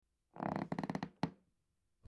Floorboards Creaking 03
Floorboards_creaking_03.mp3